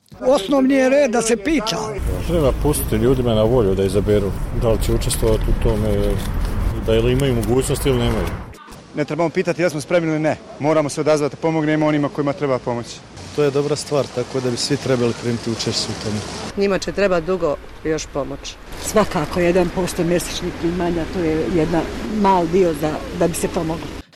Građani, pak, izražavaju solidarnost, ali mnogi od njih traže da sami odluče o načinima pomoći: